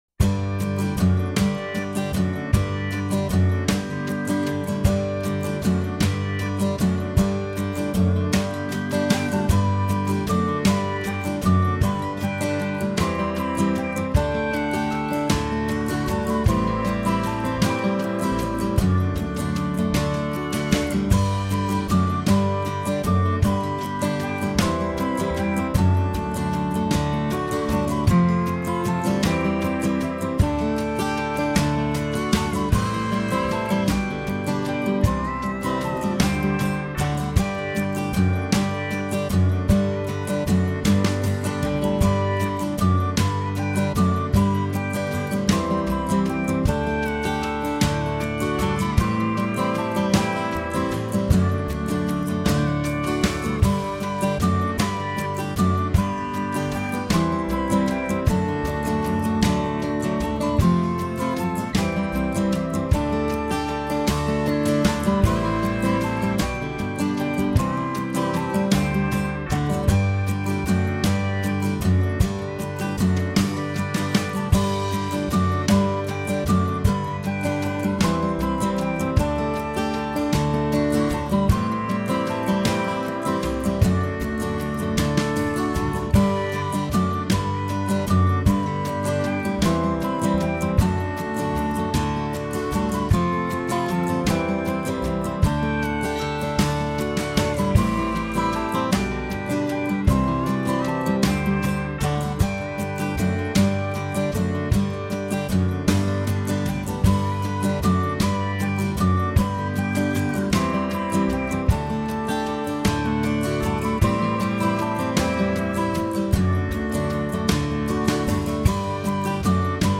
This one is so joyful it gives me goosebumps!!
My backing has fake recorder for fun.